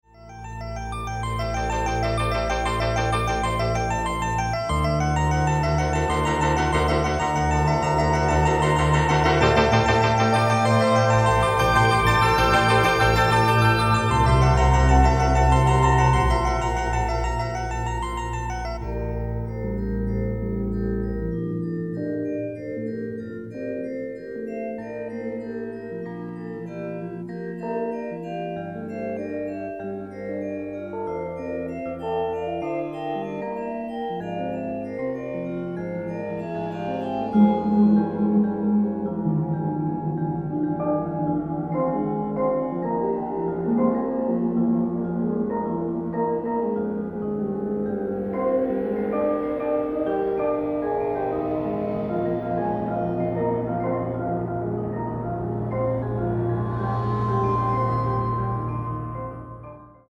Electronic tape Duration: 6 min.